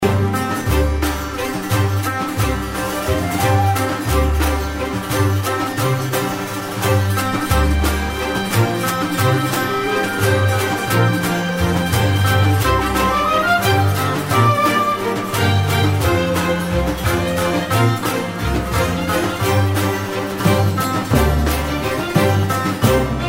رینگتون سنتی ملایم(نسبتا حماسی) و بی کلام